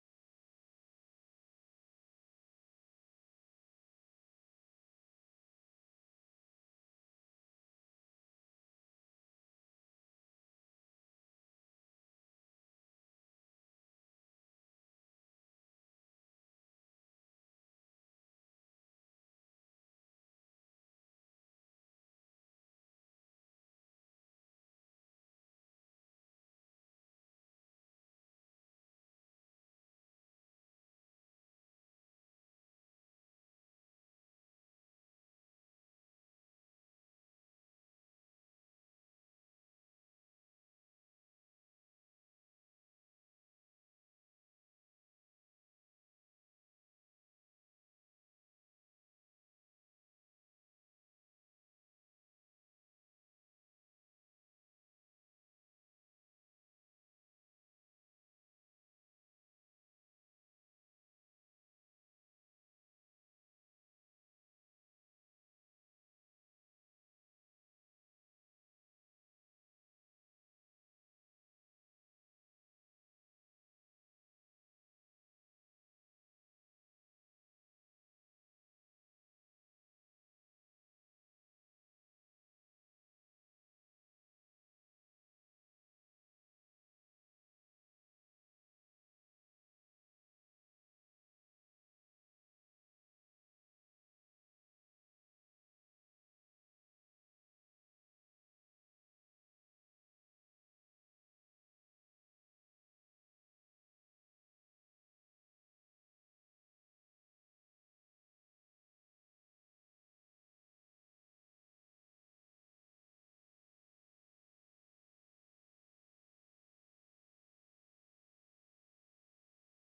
January 25, 2026 Sermon